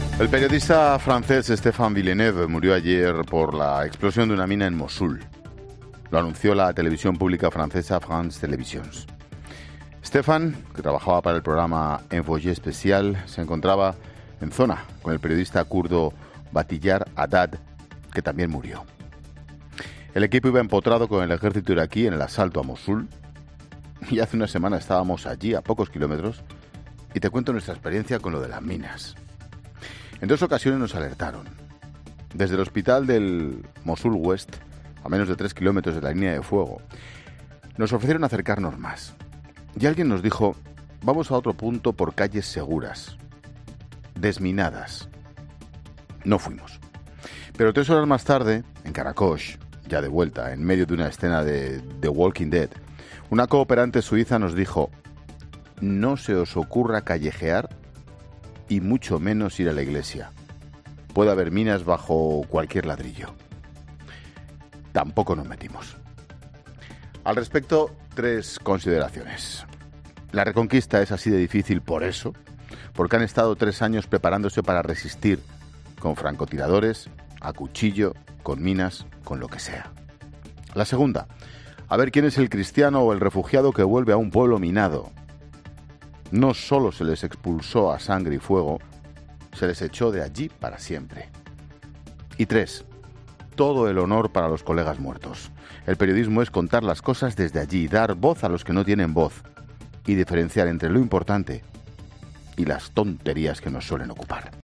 Monólogo de Expósito
Monólogo de Ángel Expósito a las 17h. apenas una semana después del su viaje a Mosul y tras la muerte de un periodista francés y su traductor kurdo en la ciudad iraquí.